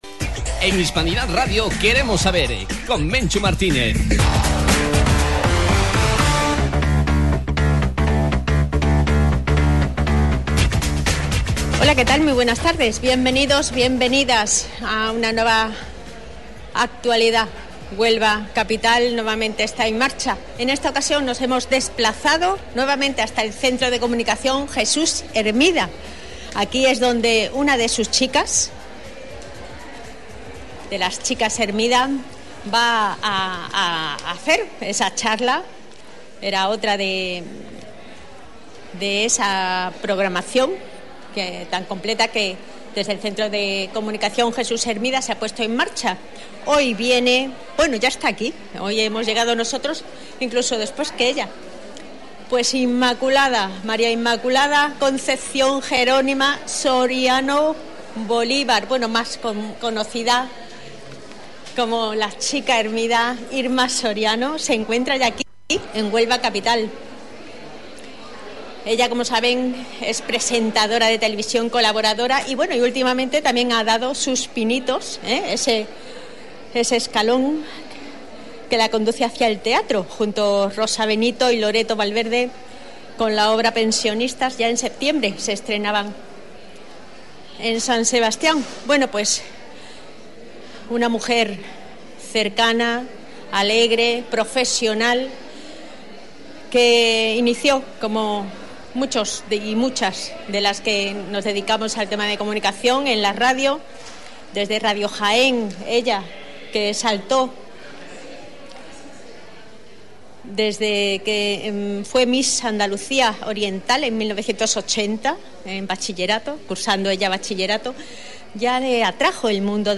La popular comunicadora ha abordado en una conferencia sus vivencias en torno al caso de Ana Orantes, un hecho transcendental en la manera de abordar la violencia de género en nuestro país